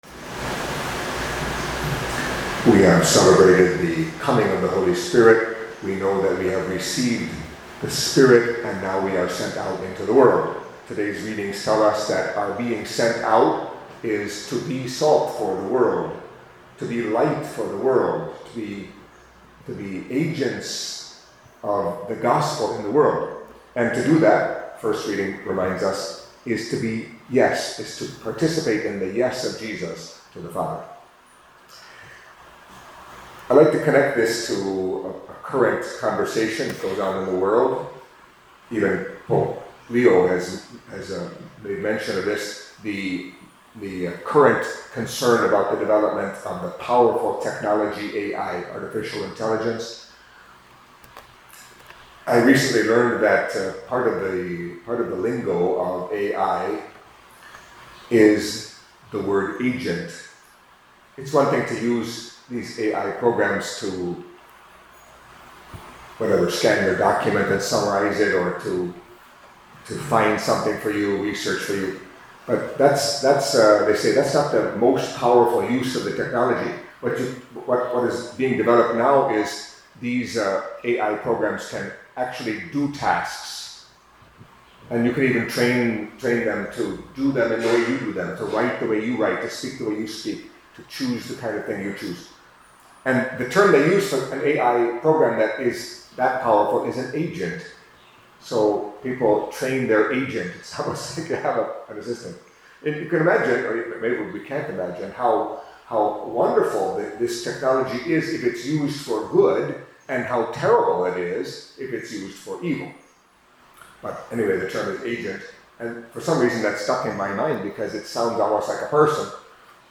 Catholic Mass homily for Tuesday of the Tenth Week in Ordinary Time